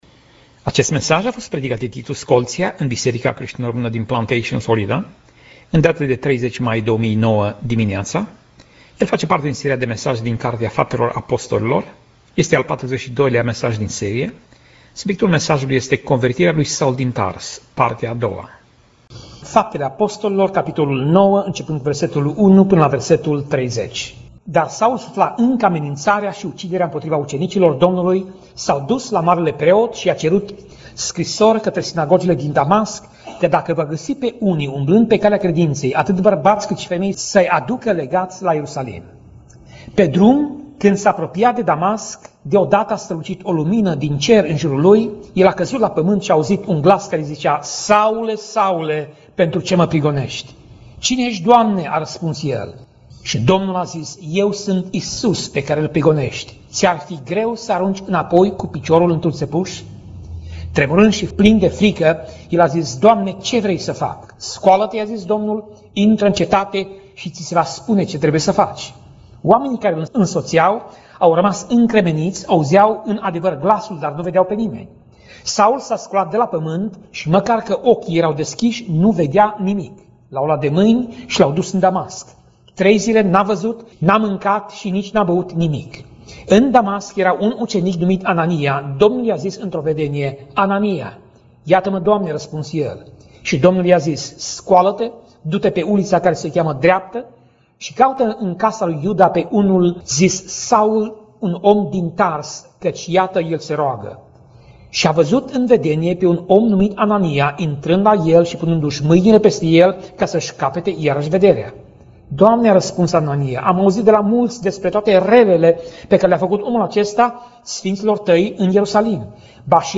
Pasaj Biblie: Faptele Apostolilor 9:1 - Faptele Apostolilor 9:22 Tip Mesaj: Predica